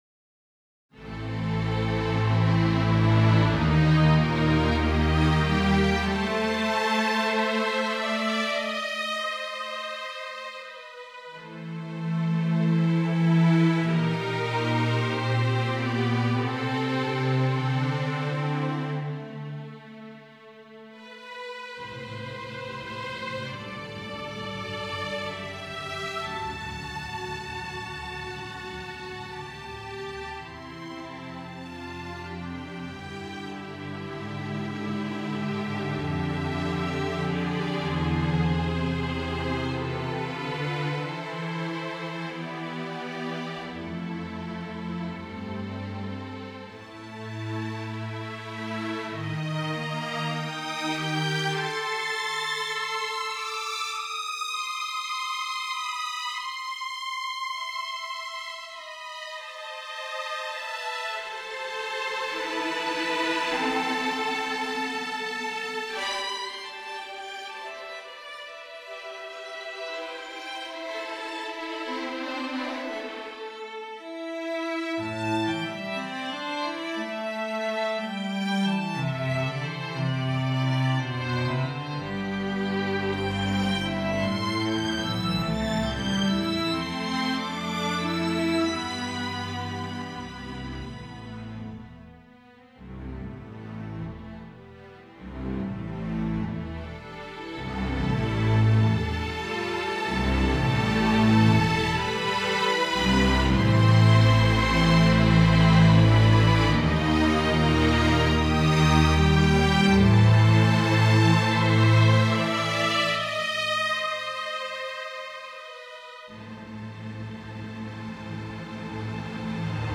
For String Orchestra